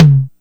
DRUMULATOR_HT.wav